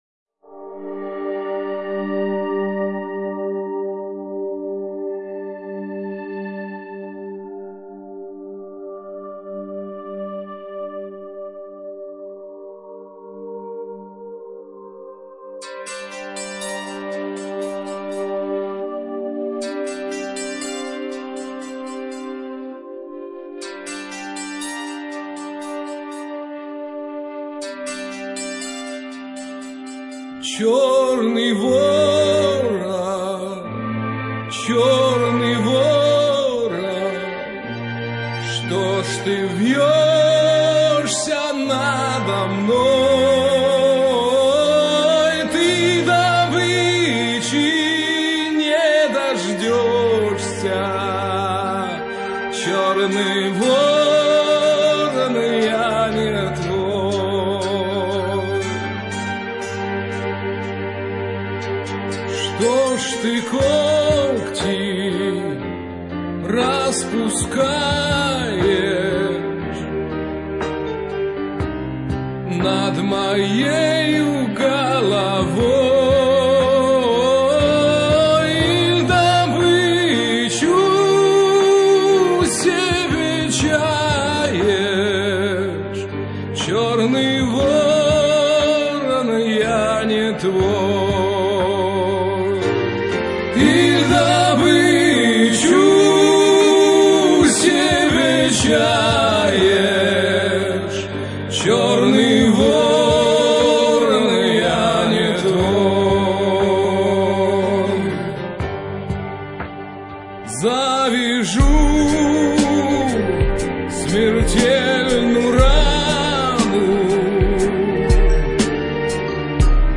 Narodnaja_Chernyj_voron_.mp3